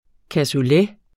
Udtale [ kasuˈlε ]